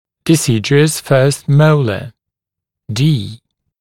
[dɪ’sɪdjuəs fɜːst ‘məulə], [diː][ди’сидйуэс фё:ст ‘моулэ]молочный первый моляр